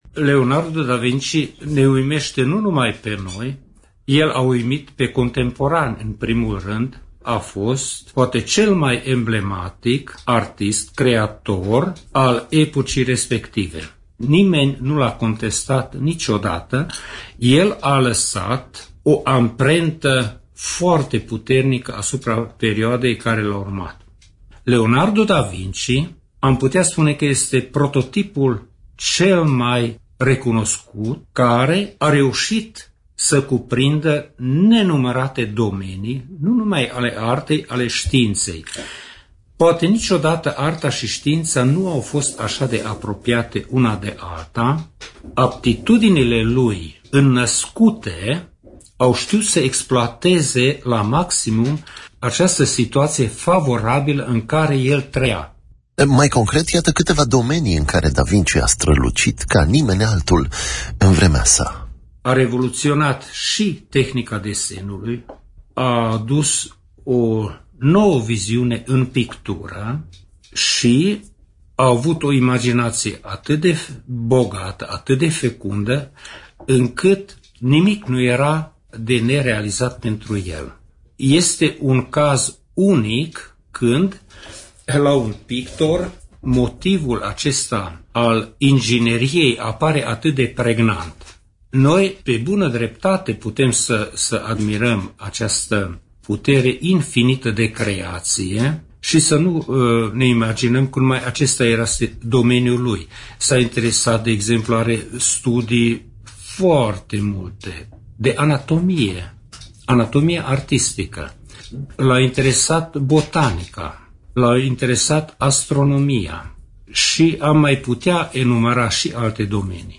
interviul